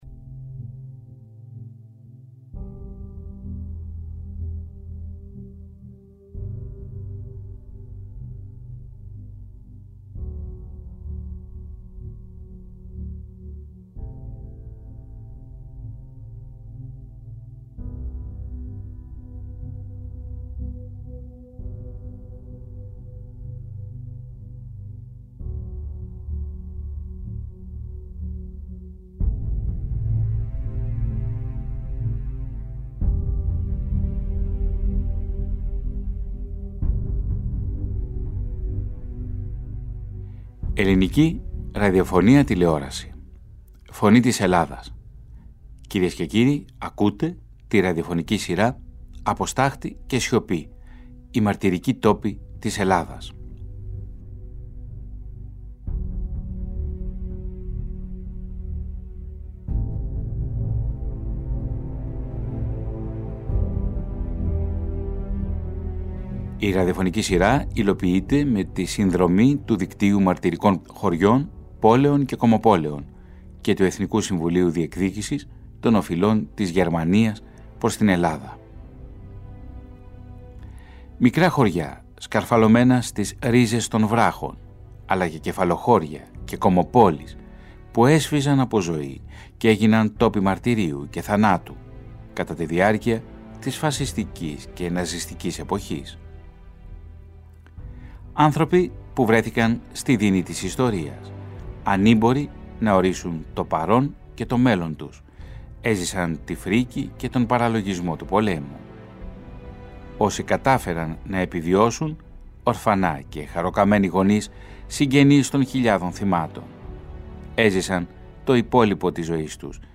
Η ΦΩΝΗ ΤΗΣ ΕΛΛΑΔΑΣ Αφυλαχτη Διαβαση Ντοκιμαντέρ ΝΤΟΚΙΜΑΝΤΕΡ